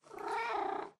purreow2.ogg